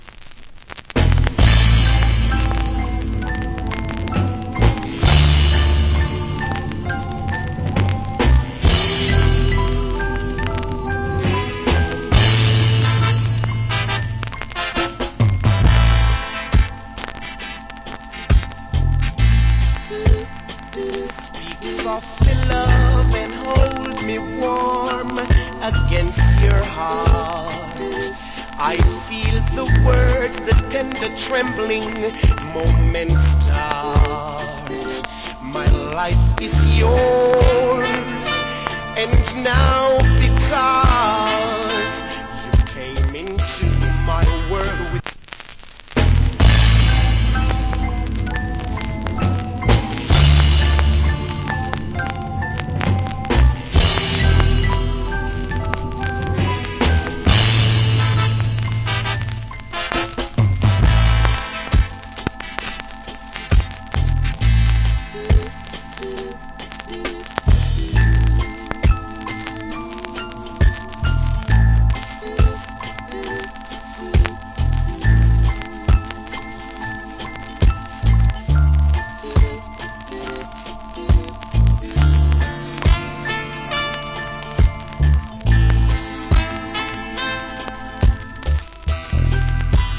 Killer Reggae Vocal.